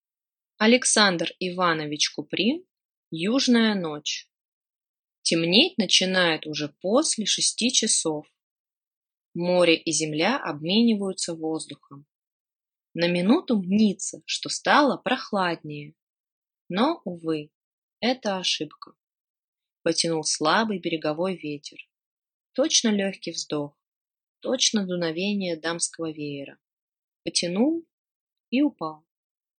Аудиокнига Южная ночь | Библиотека аудиокниг